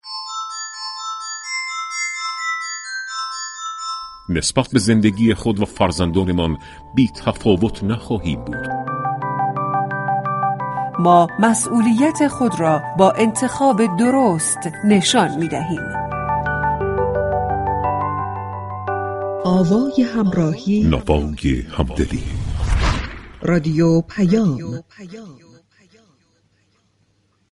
دانشگاه‌ها ملزم به تأمین امكانات لازم برای فعالیت‌های انتخاباتی تشكل‌ها هستند مراسم گرامیداشت روز دانشجو در دانشگاه آزاداسلامی واحد تهران‌مركزی معاون فرهنگی و اجتماعی وزیر علوم گفت: دانشگاه‌ها در آستانه انتخابات مجلس شورای اسلامی ملزم هستند امكانات لازم را برای برنامه‌های تشكل‌های دانشجویی فراهم كنند. به گزارش رادیو پیام، غلامرضا غفاری در گفتگو با خبرنگار مهر درباره برنامه دانشگاه ها برای حضور پرشور دانشجویان در آستانه انتخابات مجلس گفت: به دانشگاه‌ها ابلاغ كردیم اگر تشكل‌های دانشجویی، تقاضایی برای اجرای برنامه‌ای داشته باشند، امكانات لازم در چارچوب قواعد و قوانین، در اختیار آنها قرار گیرد.